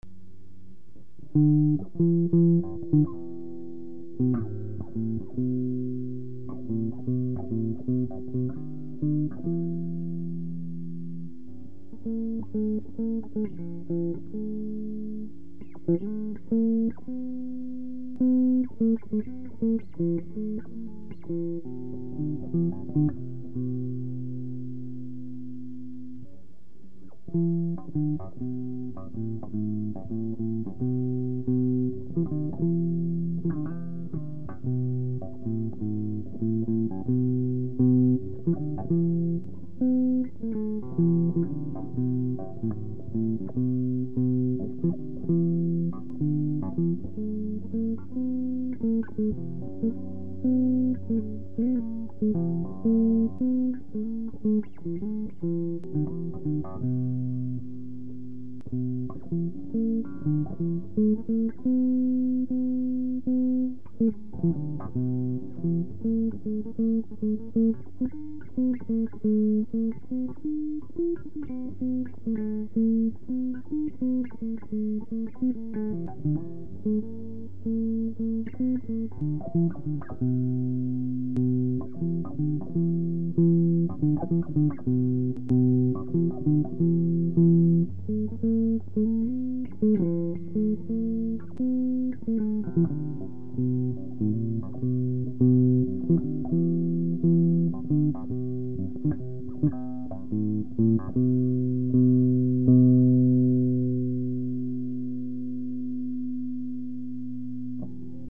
Broadly speaking, Carnatic music is written independently of the instruments it will be played on.
sample of what the music sounds like on an electric guitar. This was recorded sometime back when I could not keep beat, so this piece has no beat either. The first 25 secs form the introduction in music, the rest is adapted from Tyagaraja's grand composition Saamajavaragamana. Please bear with the recording quality, but you can do the following to mask some of the recording glitches: (i) the recording volume is low, so please turn up the volume, and (ii) keep a very bass tone.
The piece above does not attempt to reproduce the vageha version of saamajavaragamana, rather it just replaces every shuddha daivata with chatushruti daivata.
This guitar's structure is similar to a vina, it has 7 strings and 24 frets, with a deep sound and beautiful sustain as far as I am concerned.